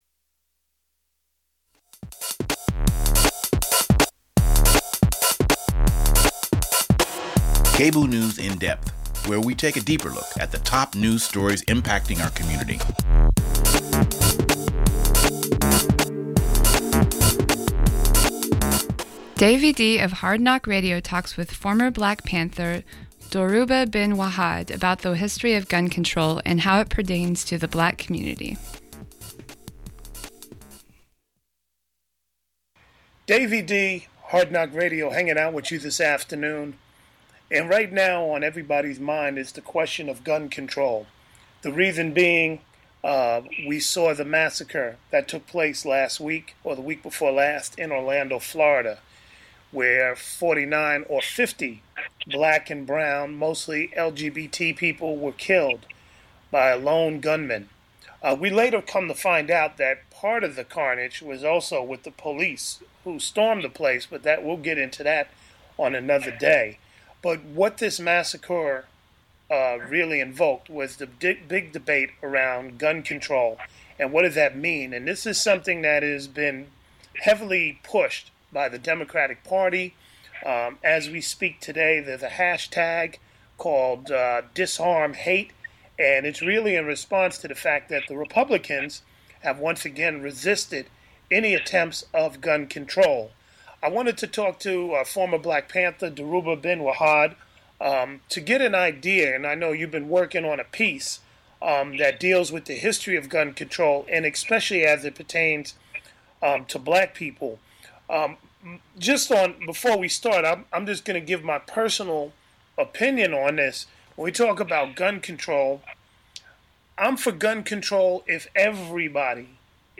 Police Chief change and what to expect in 2020: a conversation with Portland Copwatch